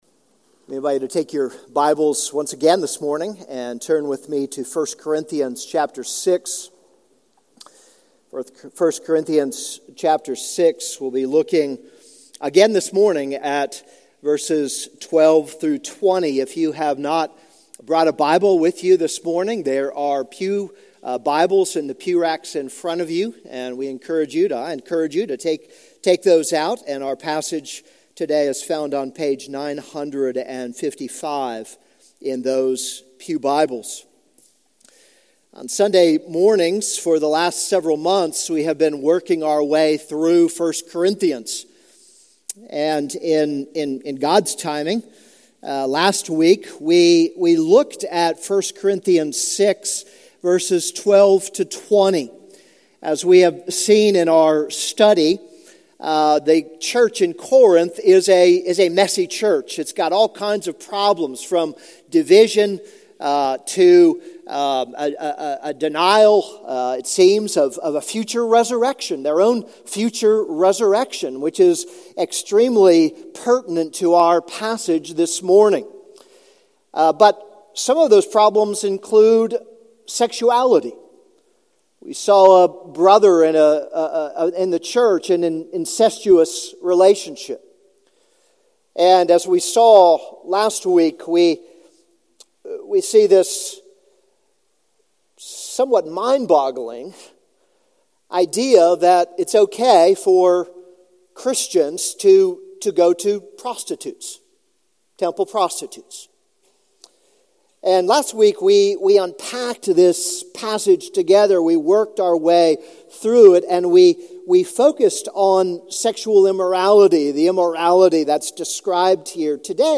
This is a sermon on 1 Corinthians 6:12-20.